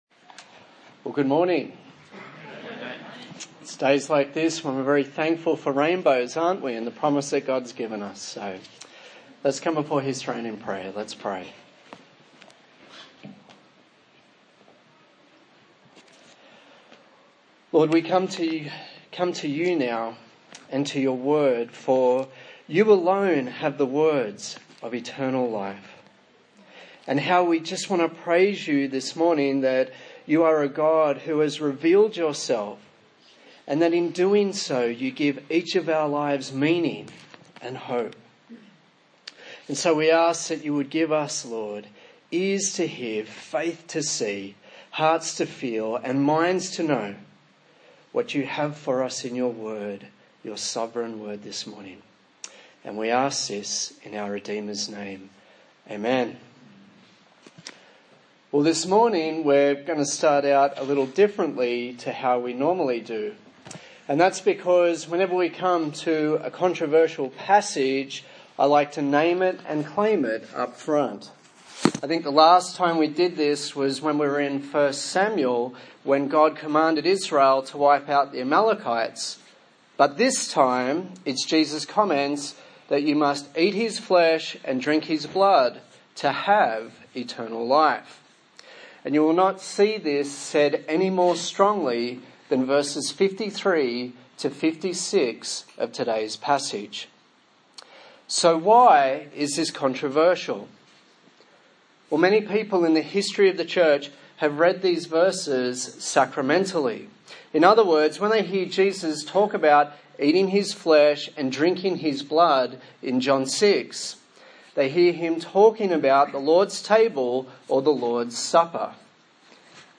John Passage: John 6:47-71 Service Type: Sunday Morning